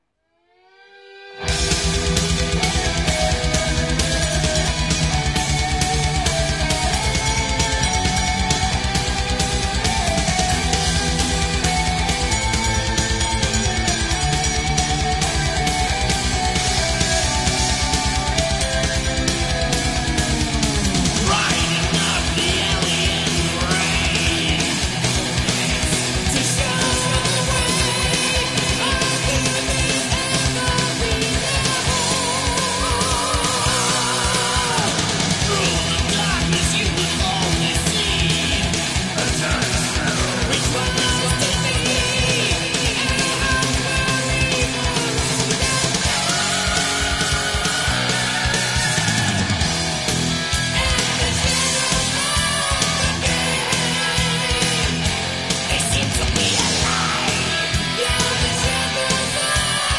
Genre:Heavy Metal Bass
Lead Guitar
Drums
Vocals